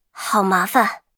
尘白禁区_安卡希雅语音_换弹.mp3